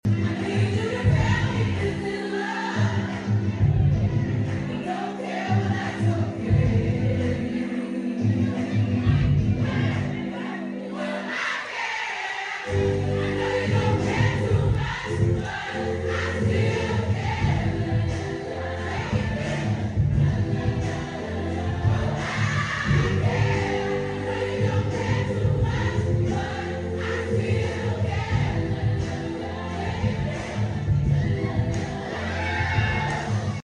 It’s me gaining the trust of the crowd through music and not even needing the mic.
I know what we are singing with full lung breath til the end of the song.